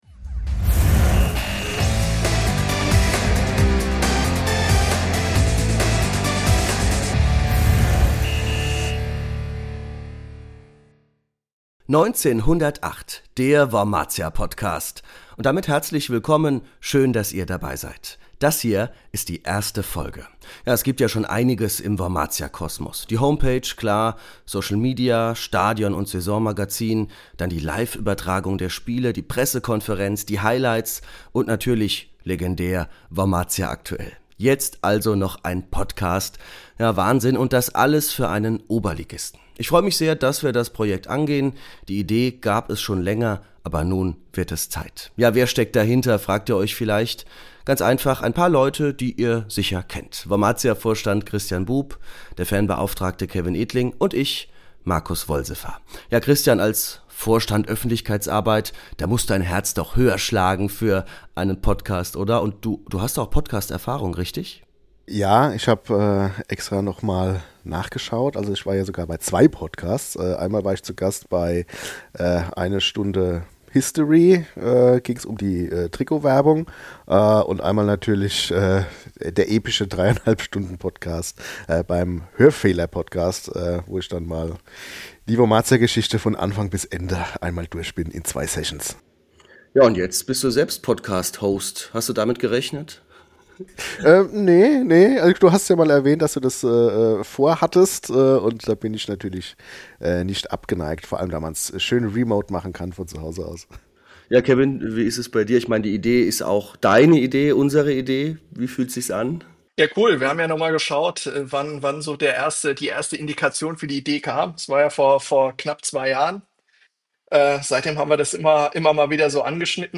Ein Gespräch über Höhen und Tiefen, unvergessliche Momente und besondere Spieler.